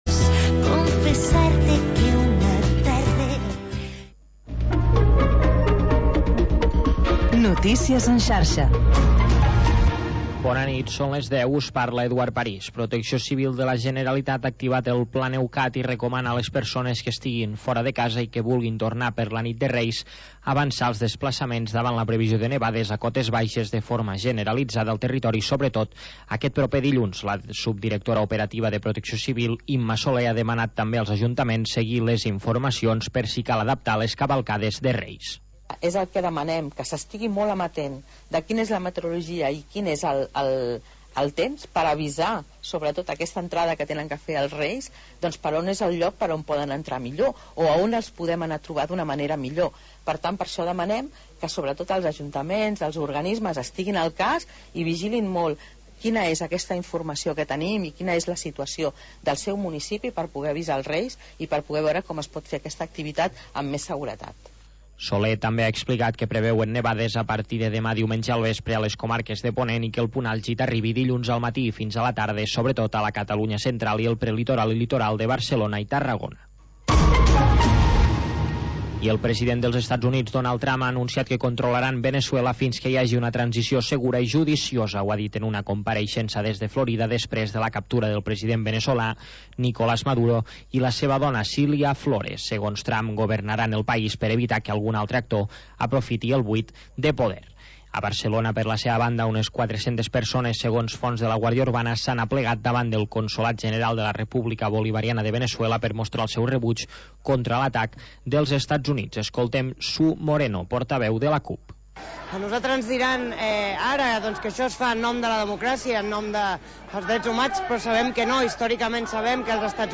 Selecció musical de Dj.